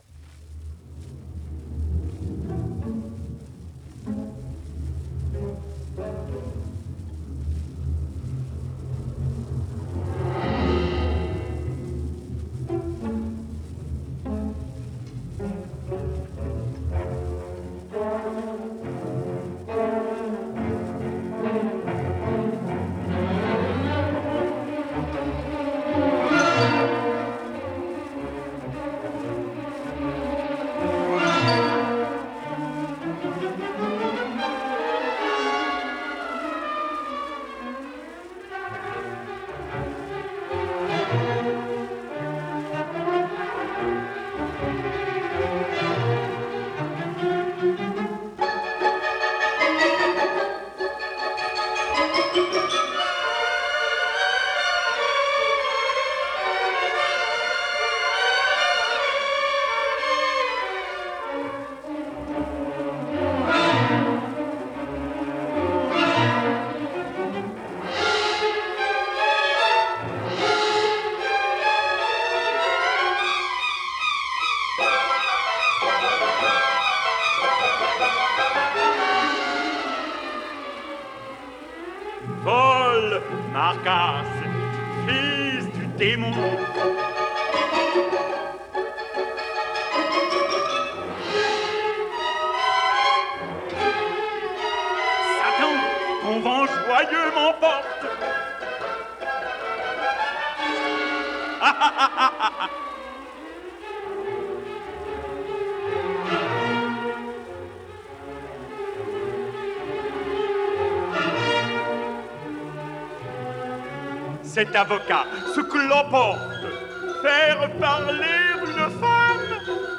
Performed shortly after the competition in this broadcast
soprano
tenor
baritone